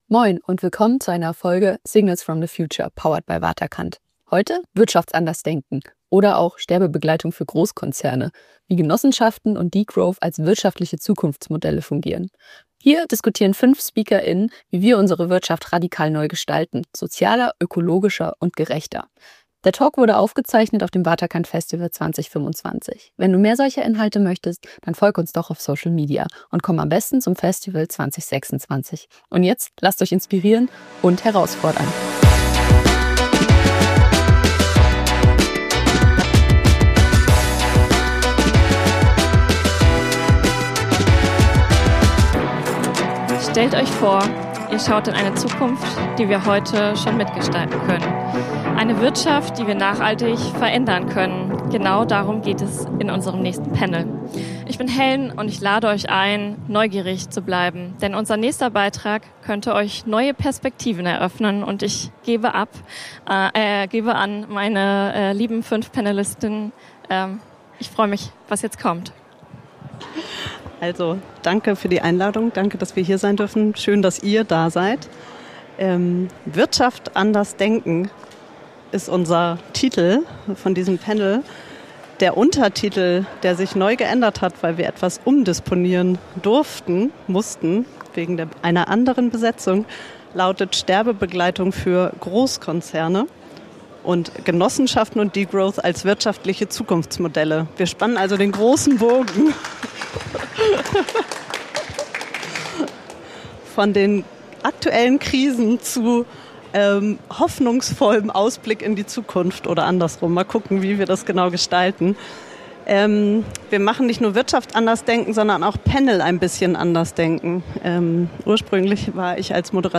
In diesem besonderen Panel diskutieren fünf Stimmen aus Wissenschaft, Praxis und Transformation darüber, wie wir Wirtschaft nicht nur anders denken, sondern auch anders leben und gestalten können: